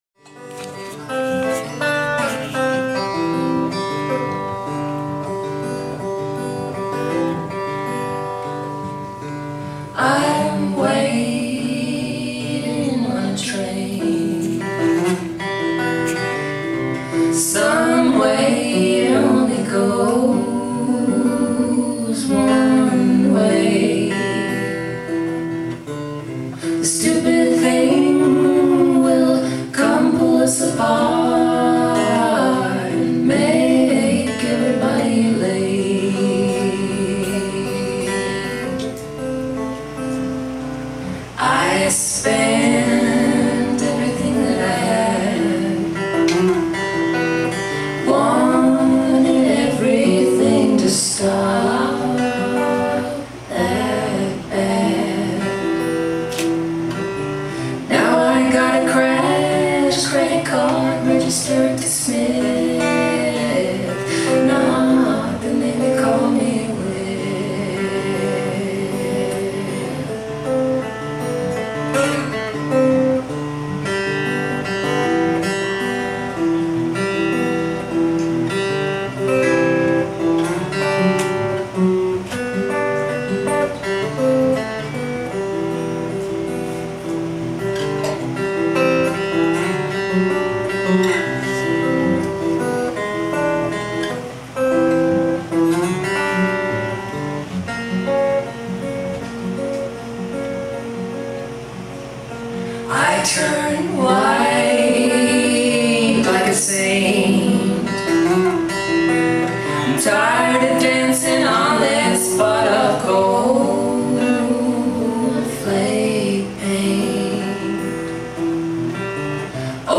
lighthearted, friendly acoustic performers